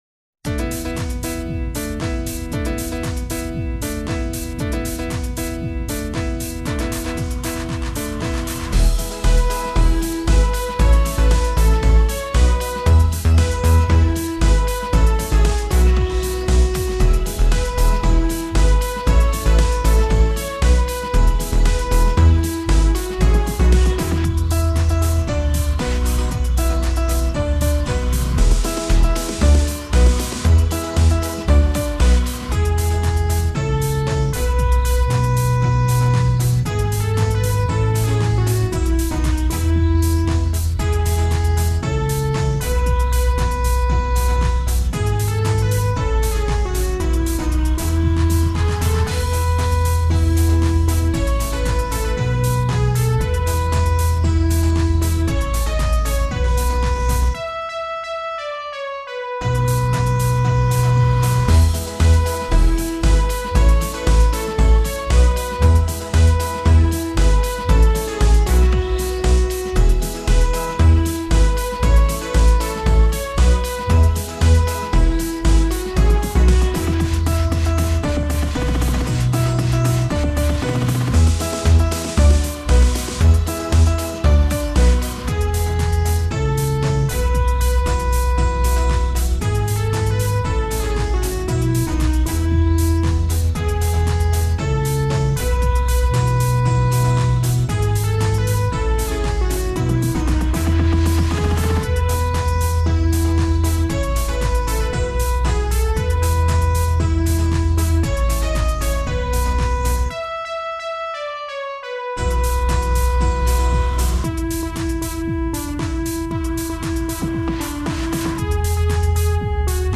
My backing is a bit electronic.